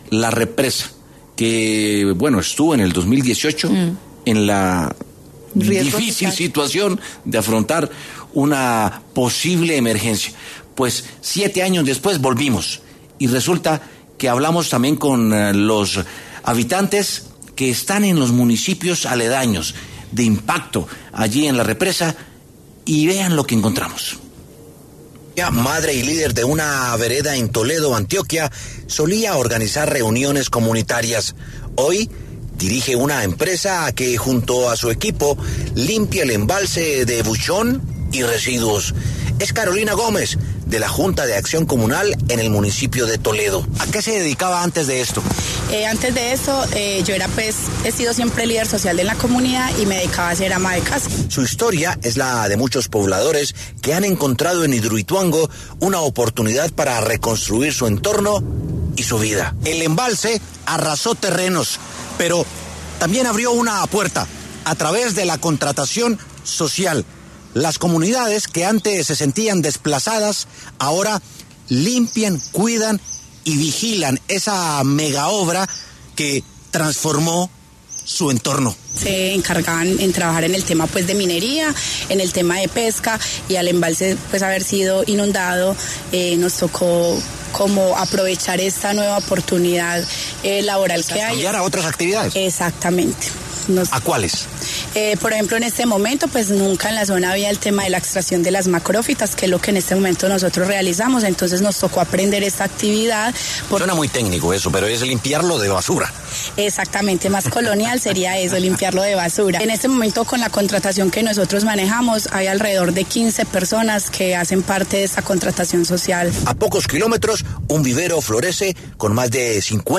En W Sin Carreta se dialogó con varios miembros de comunidades que hacen parte de la zona de influencia de Hidroituango.